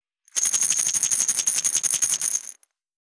370,調味料固形物,カシャカシャ,サラサラ,パラパラ,
効果音厨房/台所/レストラン/kitchen